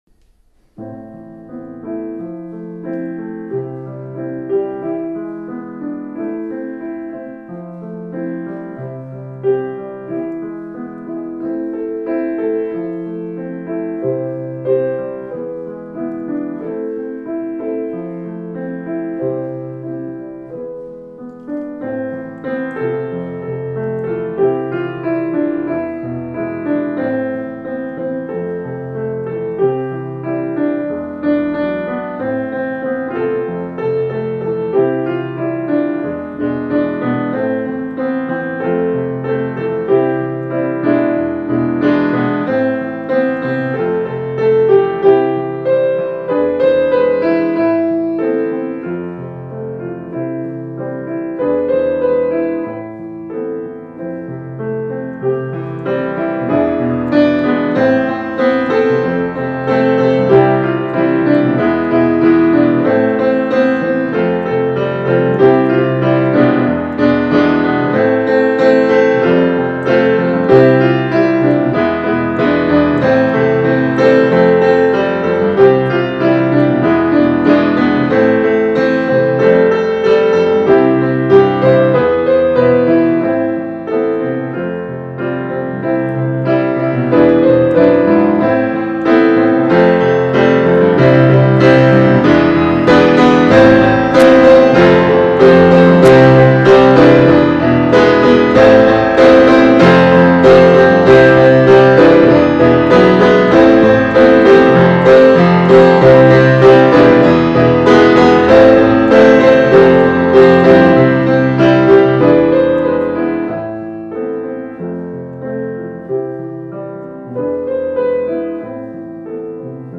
Хорошо звучит вне зависимости от инструмента.